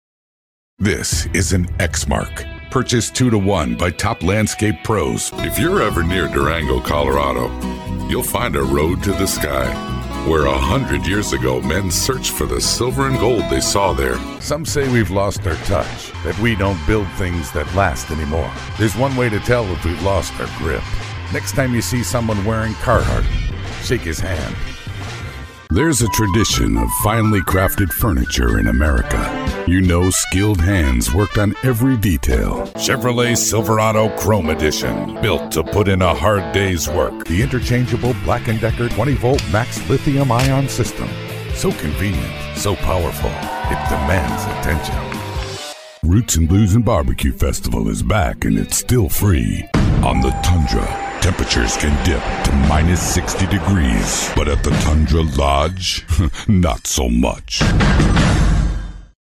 Male Voice Talent | Male Voice Actor | Voiceover Talent | Voice over Talent